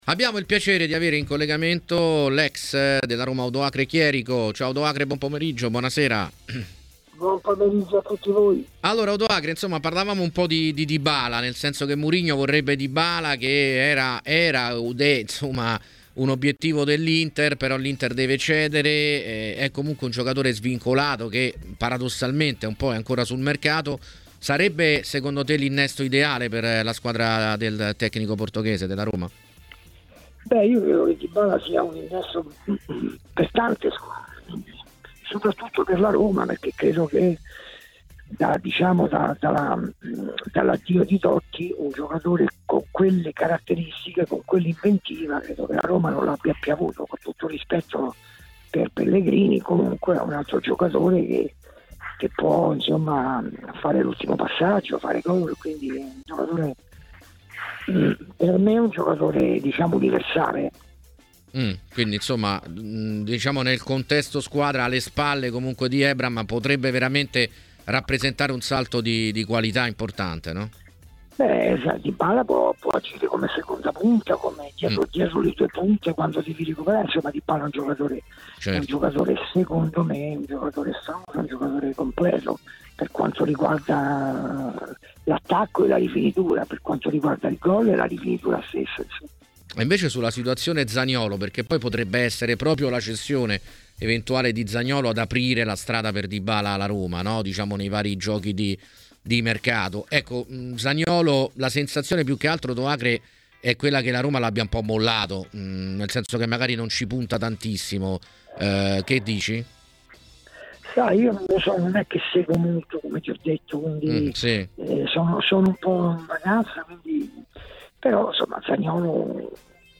Odoacre Chierico, ex calciatore della Roma, è intervenuto ai microfoni di A tutto mercato su TMW Radio per commentare l'attualità del mercato giallorosso: